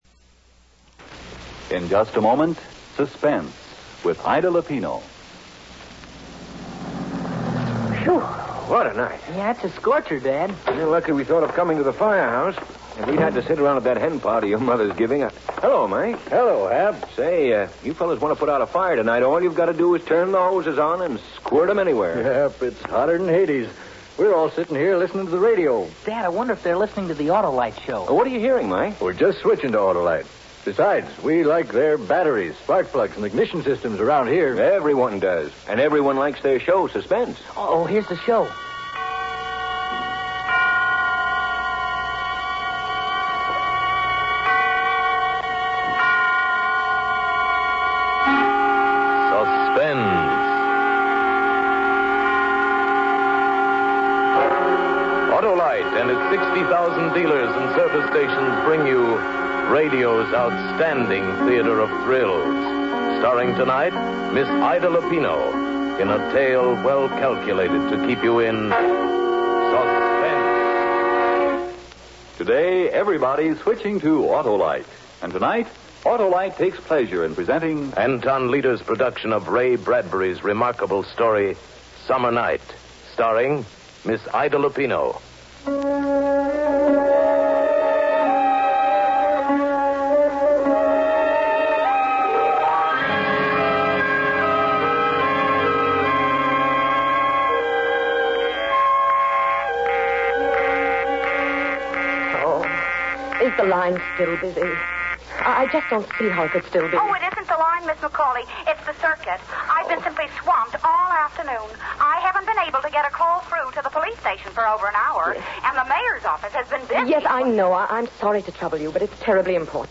old time radio